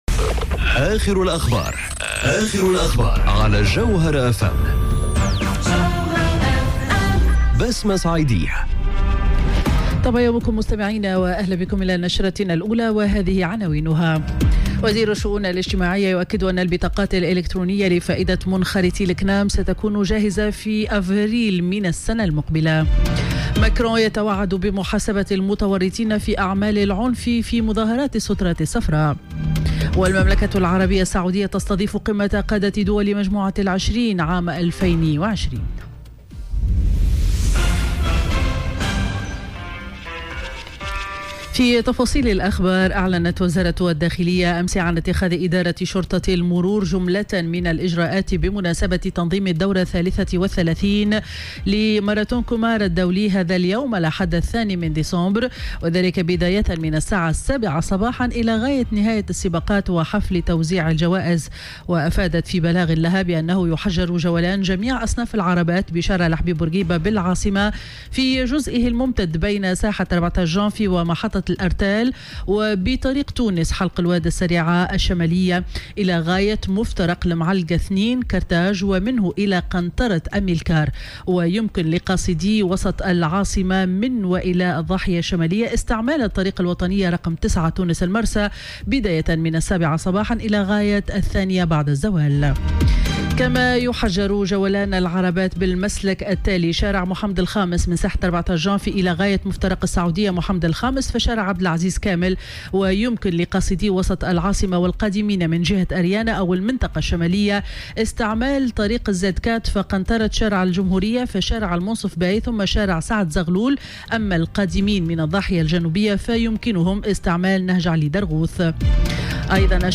نشرة أخبار السابعة صباحا ليوم الأحد 2 ديسمبر 2018